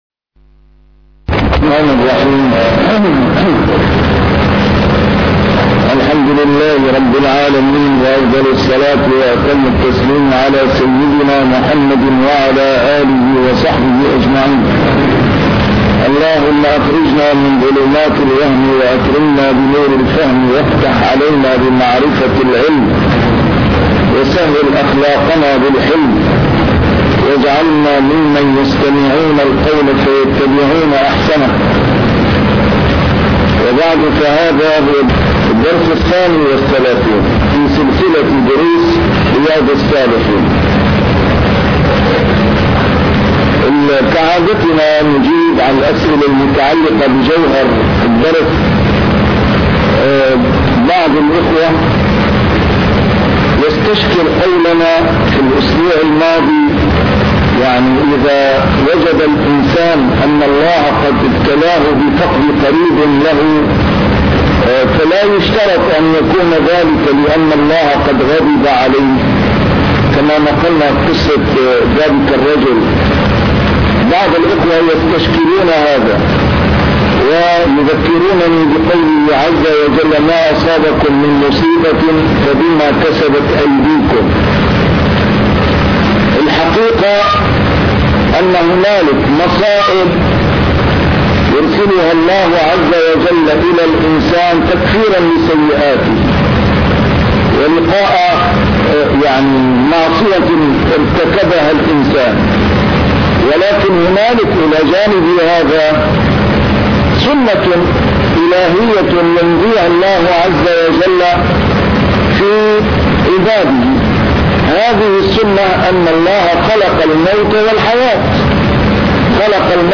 A MARTYR SCHOLAR: IMAM MUHAMMAD SAEED RAMADAN AL-BOUTI - الدروس العلمية - شرح كتاب رياض الصالحين - 32- شرح رياض الصالحين: الصبر